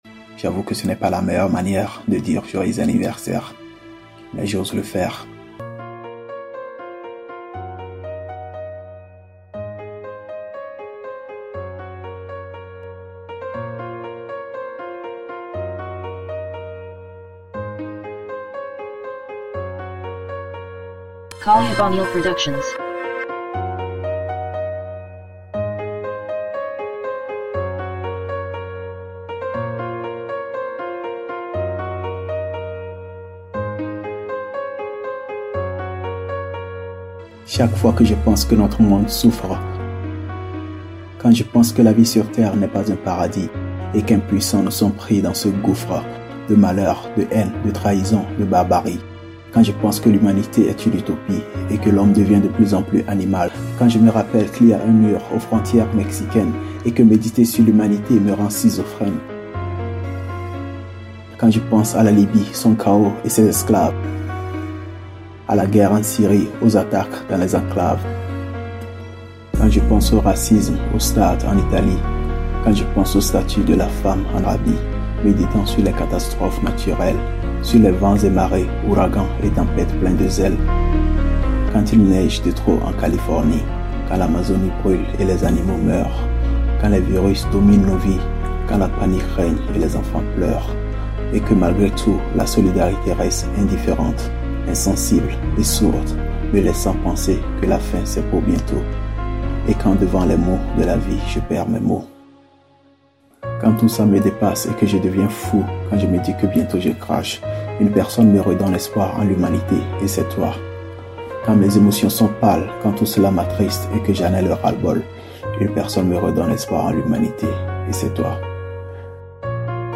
Beat originel modifié et utilisé